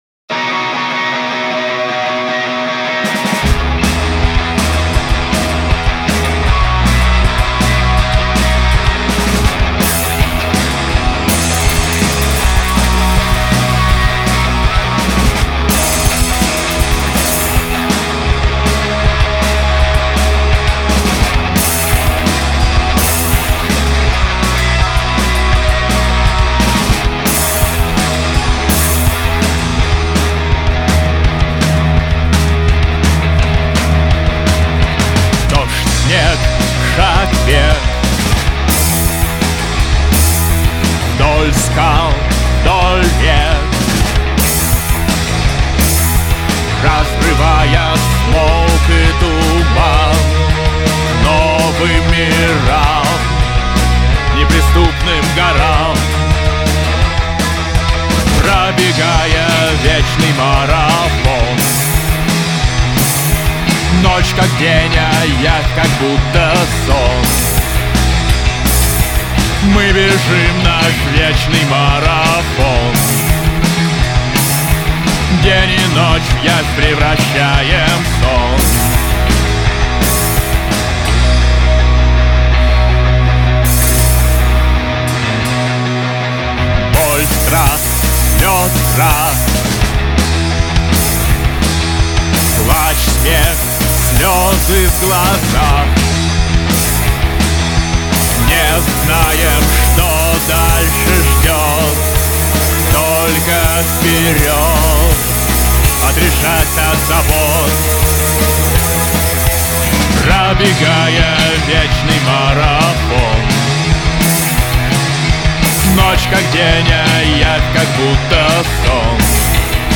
постпанк-группа